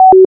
hex_beepoff.ogg